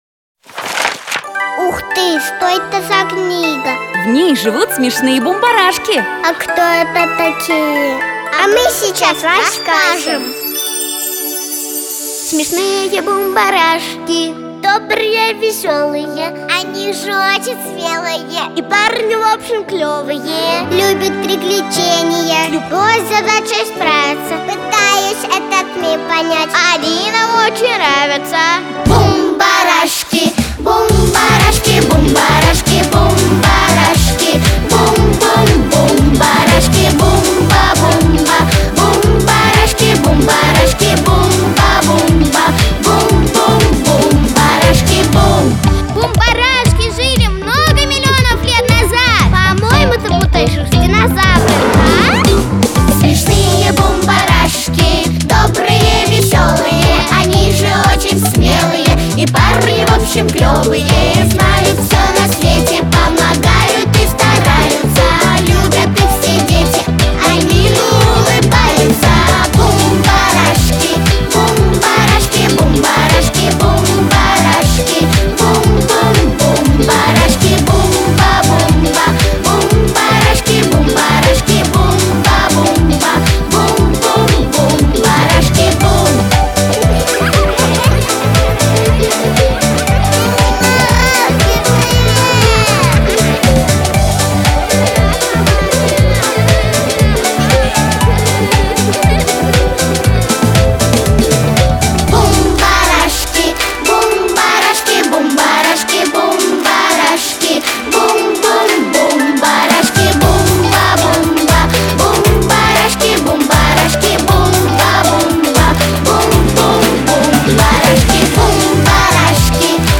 • Категория: Детские песни
детская дискотека